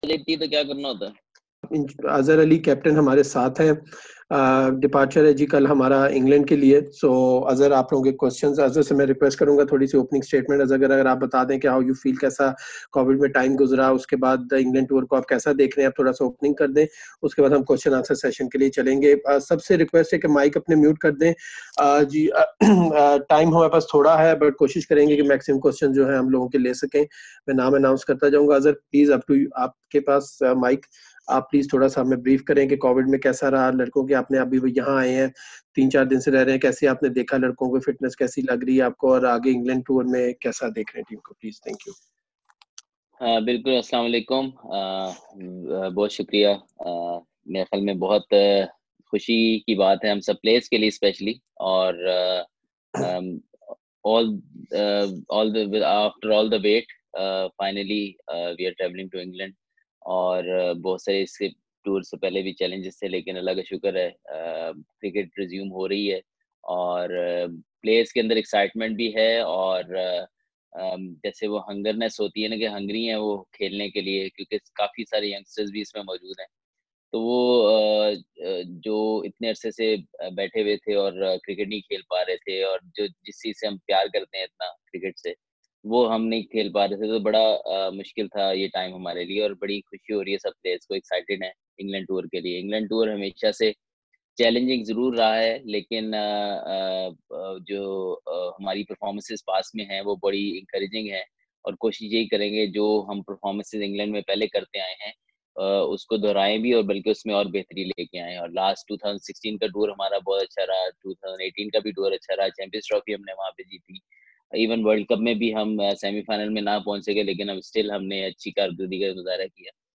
Pakistan Cricket Board Chief Executive Wasim Khan and Test captain Azhar Ali held virtual media conferences on Saturday afternoon.